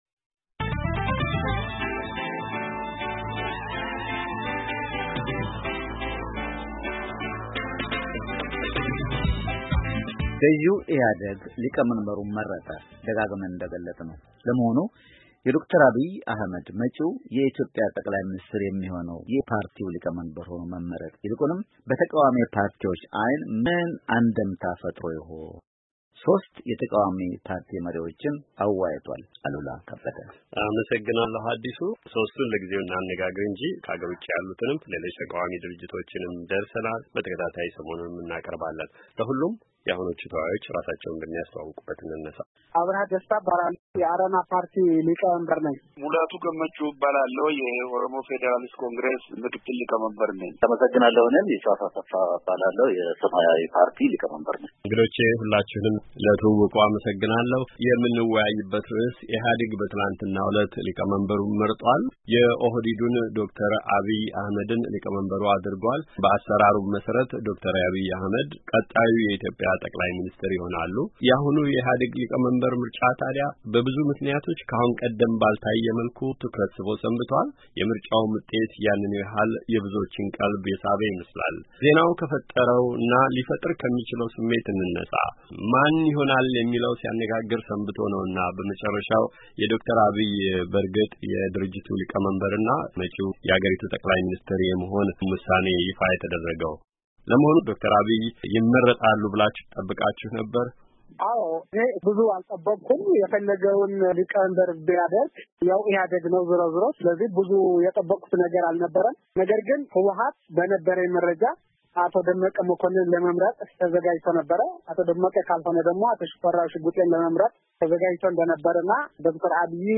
ውይይት:- የመጪው የኢትዮጵያ ጠቅላይ ሚንስትር መሰየምና አንድምታ ..